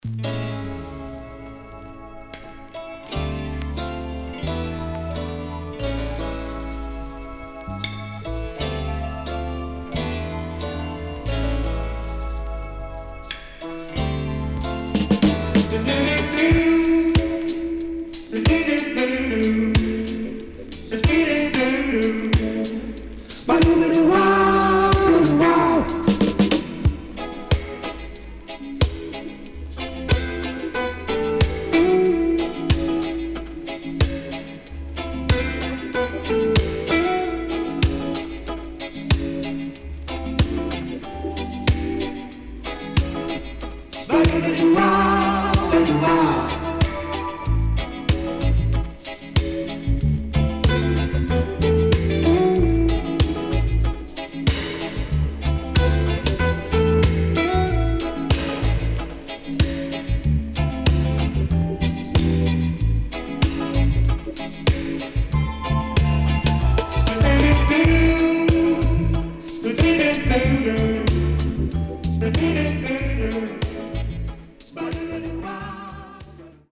【12inch】
SIDE A:所々チリノイズがあり、少しプチノイズ入ります。